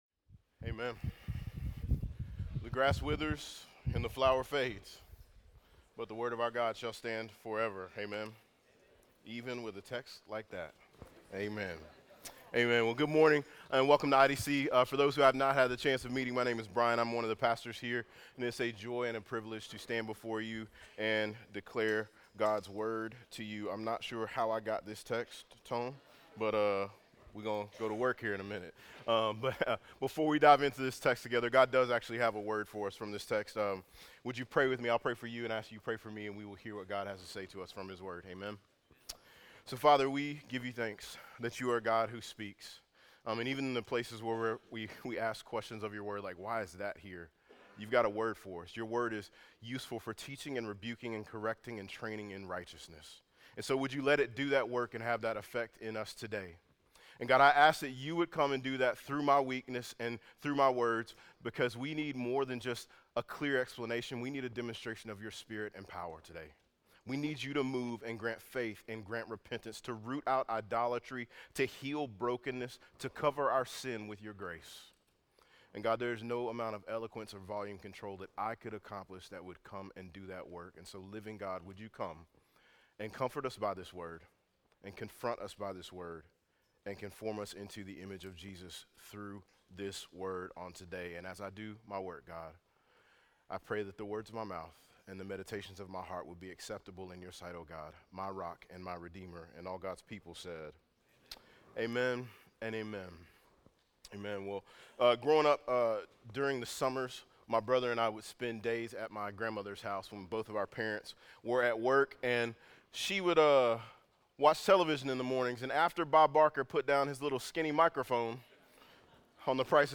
Sermons from Imago Dei Church in Raleigh North Carolina